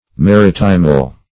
maritimal - definition of maritimal - synonyms, pronunciation, spelling from Free Dictionary
Search Result for " maritimal" : The Collaborative International Dictionary of English v.0.48: Maritimal \Ma*rit"i*mal\, Maritimale \Ma*rit"i*male\ (m[.a]*r[i^]t"[i^]*mal), a. See Maritime .